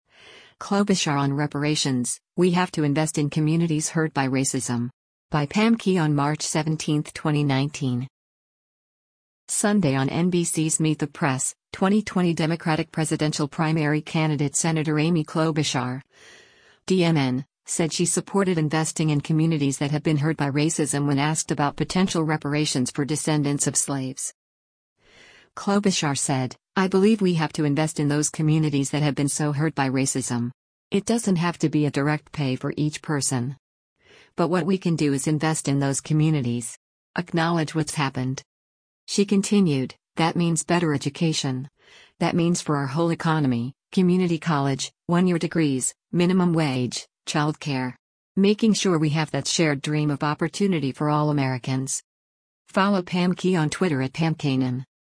Sunday on NBC’s “Meet the Press,” 2020 Democratic presidential primary candidate Sen. Amy Klobuchar (D-MN) said she supported investing in communities that have been hurt by racism when asked about potential reparations for descendants of slaves.